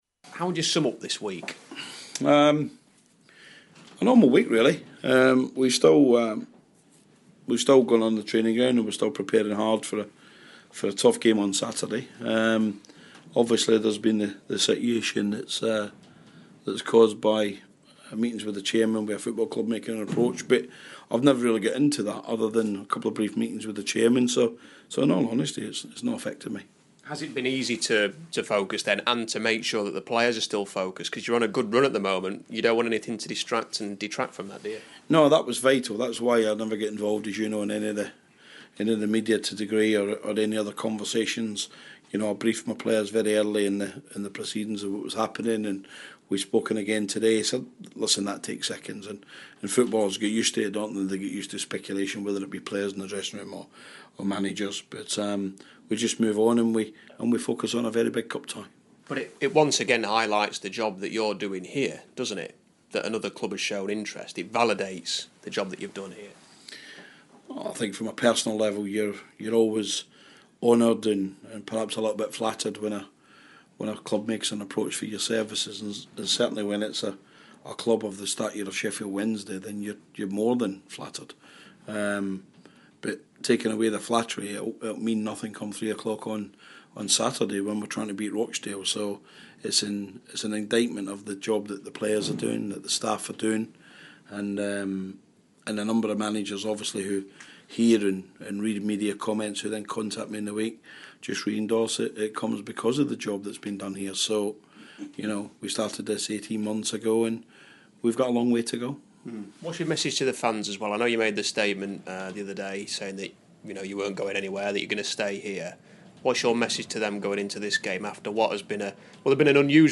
Interview: Rotherham boss Steve Evans pre-Rochdale